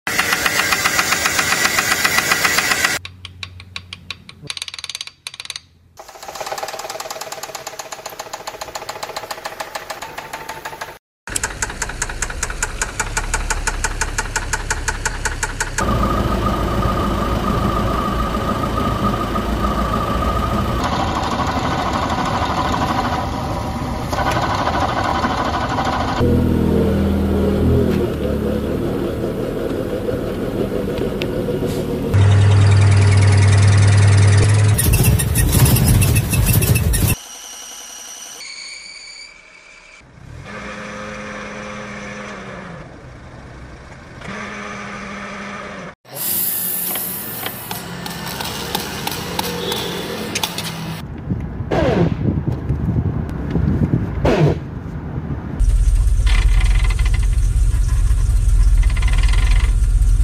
Car problems sounds part 12 sound effects free download
car problem sounds engine noise issues vehicle troubleshooting strange car noises car diagnostic engine knocking transmission noise brake failure sounds suspension problems exhaust noise weird car sounds